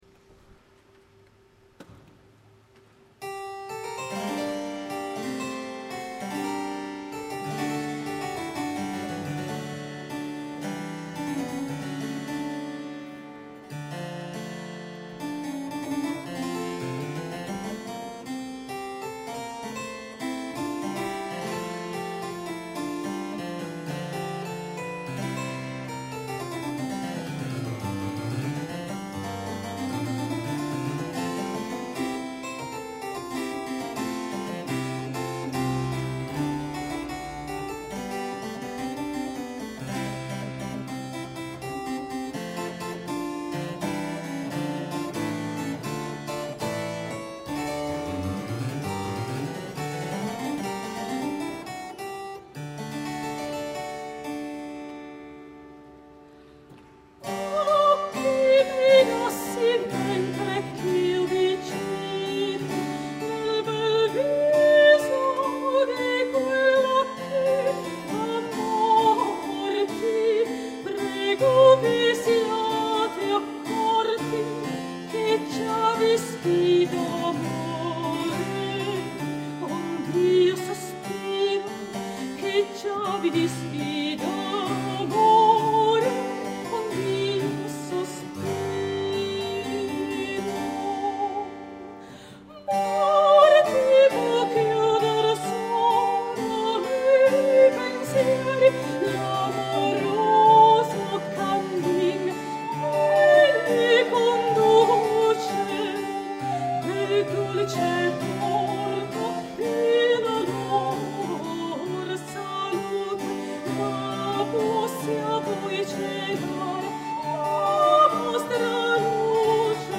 Templo de la Valenciana - Guanajato-Mexico - Festival Internacional Cervantino, 4 ottobre 2007
CONSORT VENETO:
soprano
flauto
Dulciana
cembalo
Registrazione a Cura di Radio Educàtion Mexico